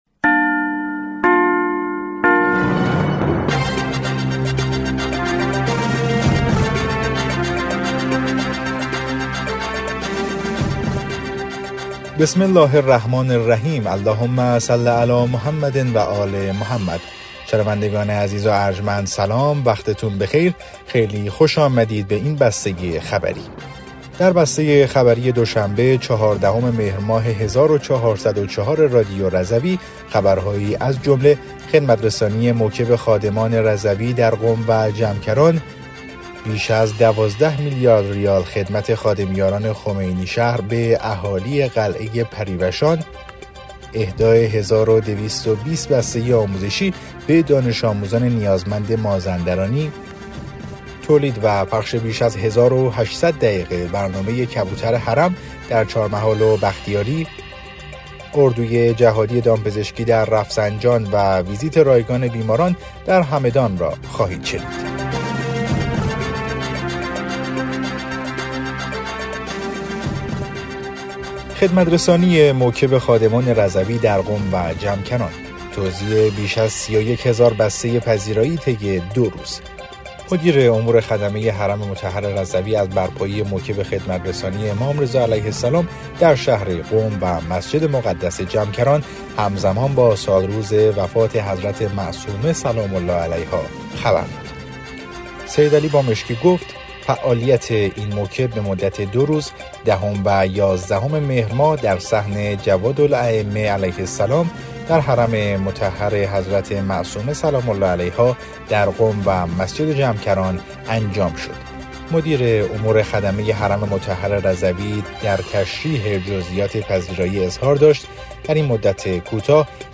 بسته خبری ۱۴ مهر ۱۴۰۴ رادیو رضوی؛